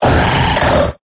Cri de Kaorine dans Pokémon Épée et Bouclier.